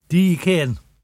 [duv-thee-kEHn]